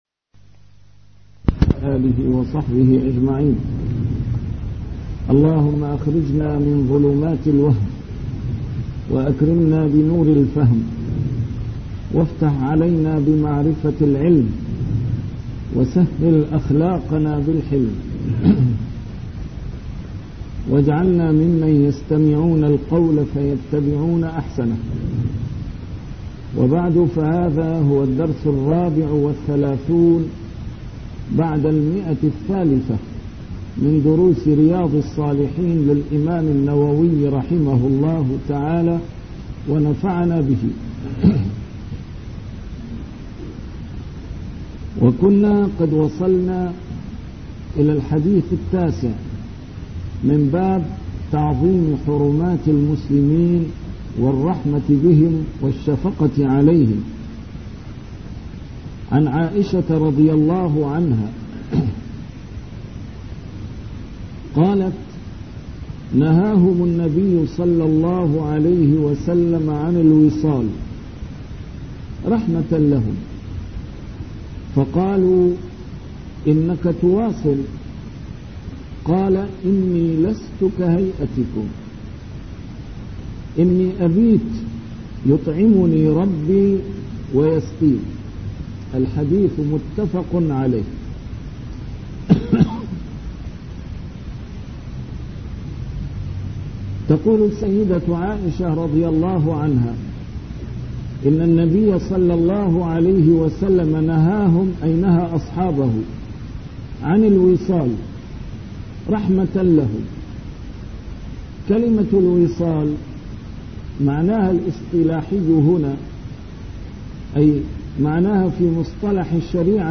A MARTYR SCHOLAR: IMAM MUHAMMAD SAEED RAMADAN AL-BOUTI - الدروس العلمية - شرح كتاب رياض الصالحين - 334- شرح رياض الصالحين: تعظيم حرمات المسلمين